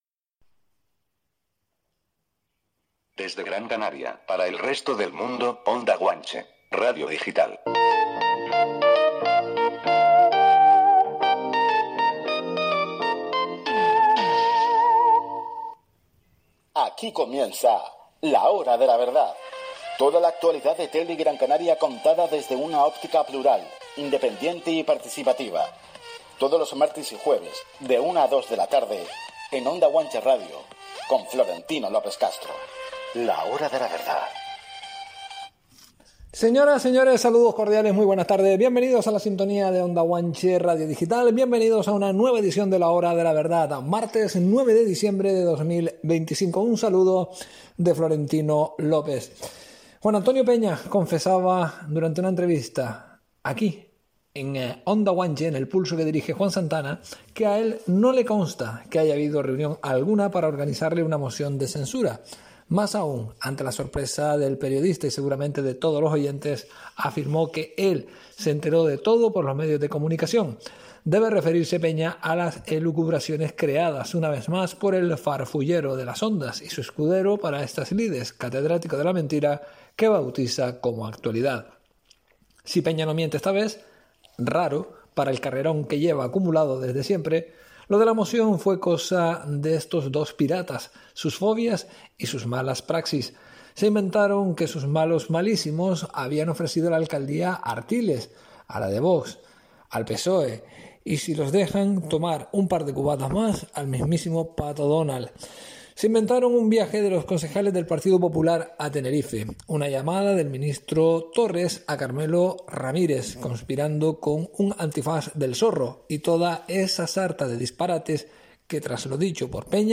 una entrevista con el concejal, portavoz y presidente de Nueva Canarias en Telde, José Luis Macías, que repasará todas las cuestiones de interés municipal y orgánico de su formación.